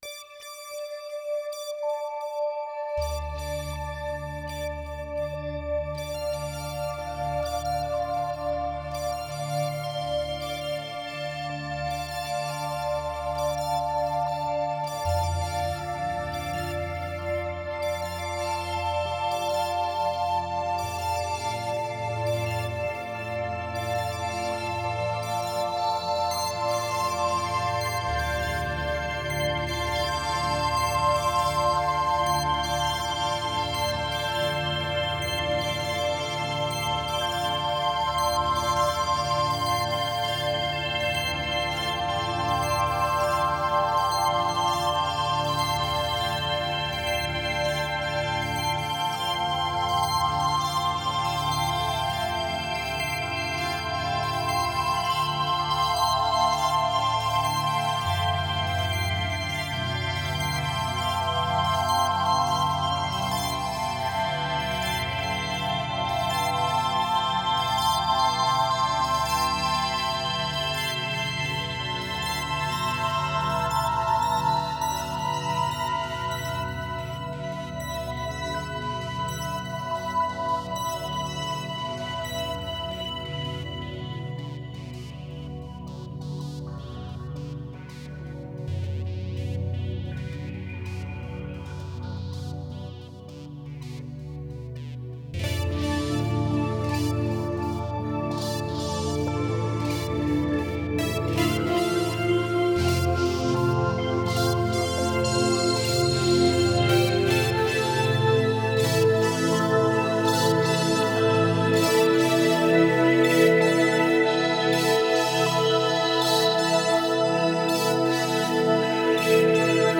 Медитативная музыка Мистическая музыка Духовная музыка